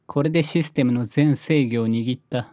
ハッカー ボイス 声素材 – Hacker Cracker Voice
Voiceボイス声素材